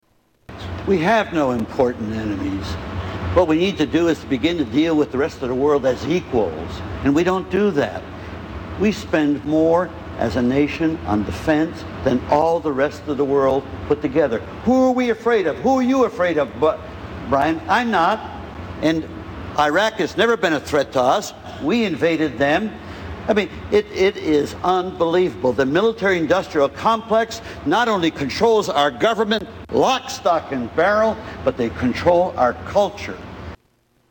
Tags: Political Mike Gravel Presidential Candidate Democratic Mike Gravel Speeches